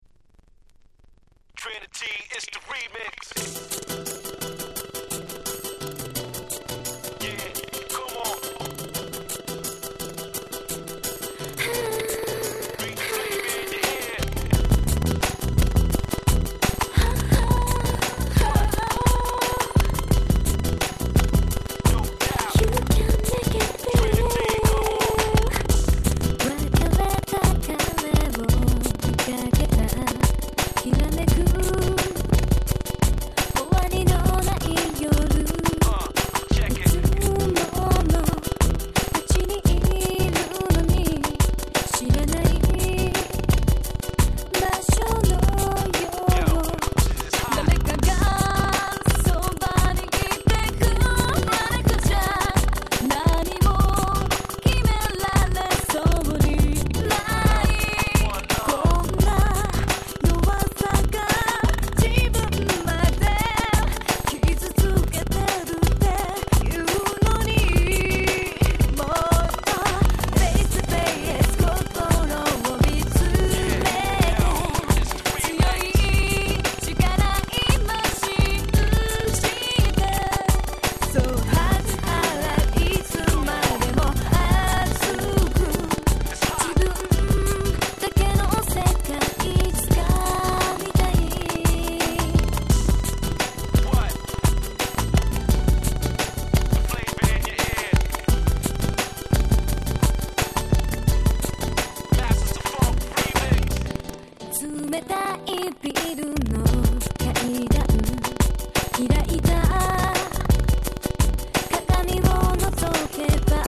03' Nice Japanese R&B !!